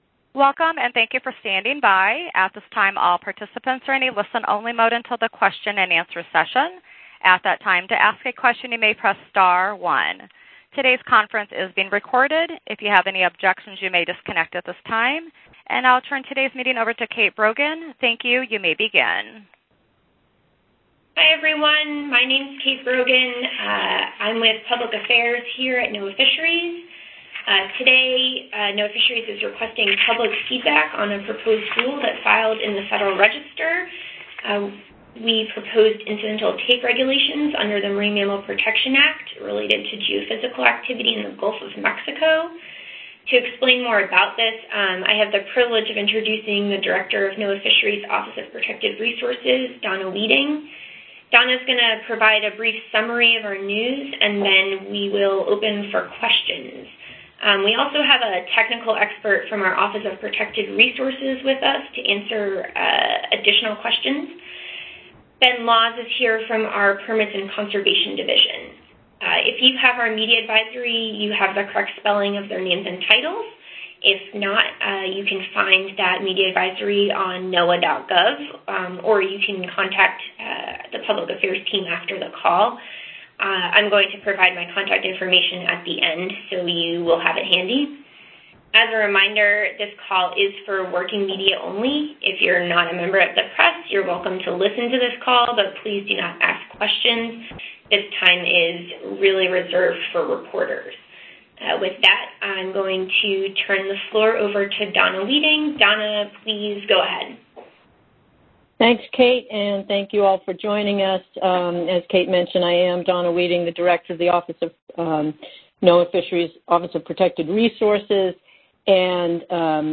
Media teleconference to take place June 21